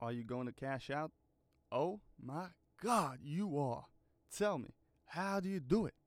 Voice Lines
Are you going to cash out_ oh my god you are.wav